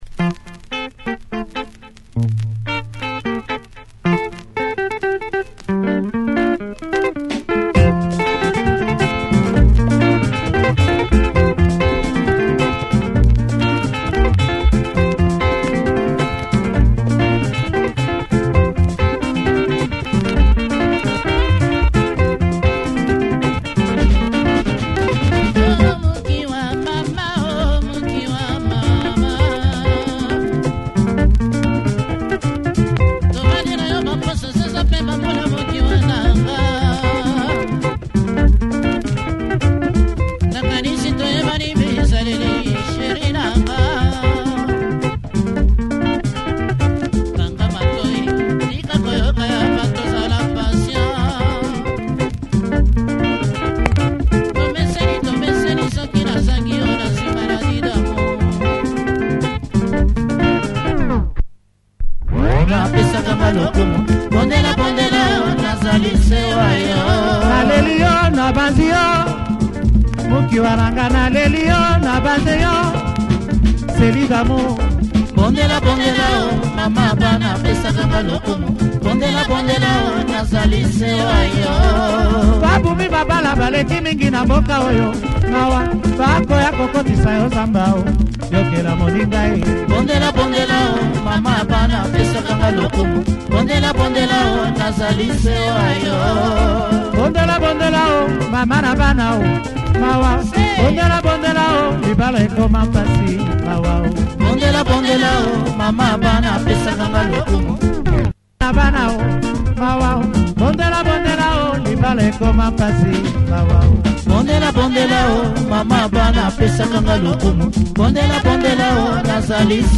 Good Lingala track with great intricate guitar play.